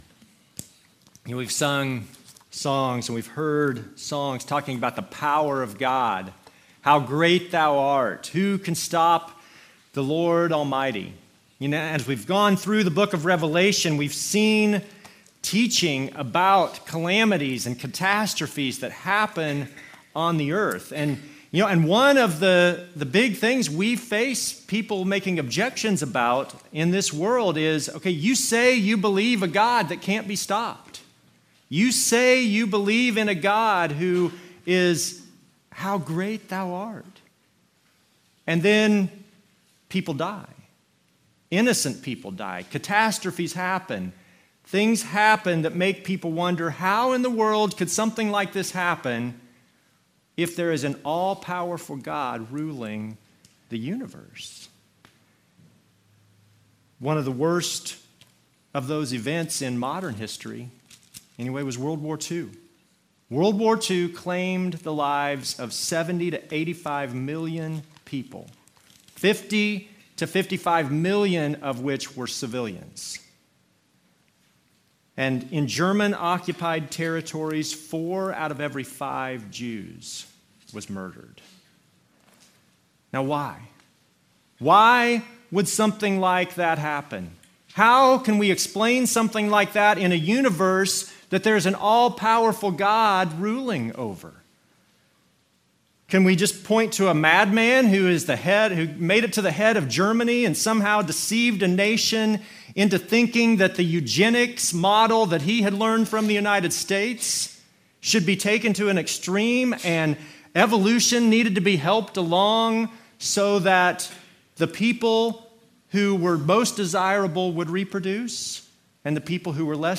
Passage: Revelation 12 Service Type: Normal service